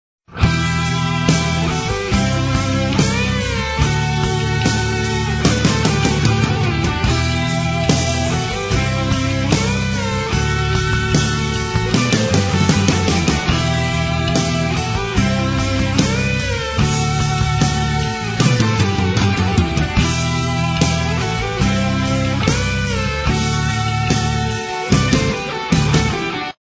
Ещё один шедевр мировой рок - музыки .